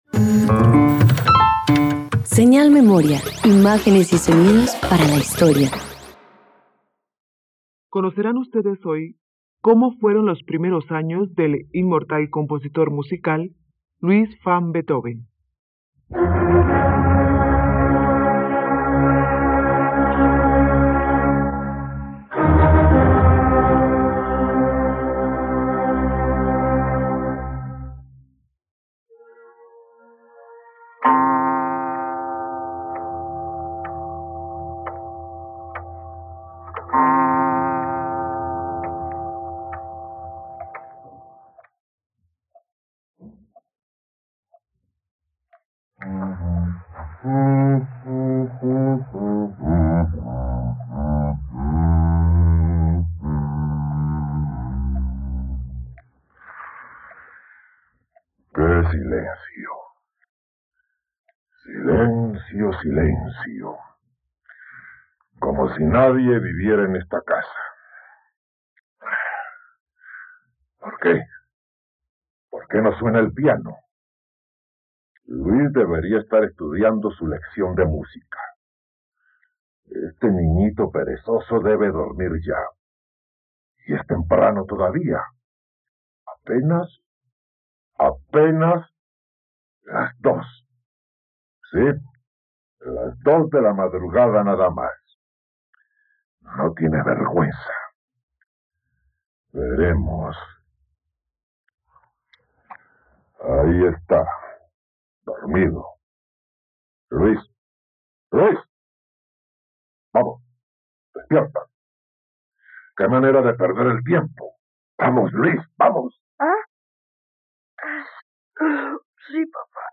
..Disfruta la adaptación radiofónica de la vida del compositor, director de orquesta y pianista alemán Ludwig Van Beethoven.